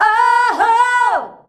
OOOHOO  B.wav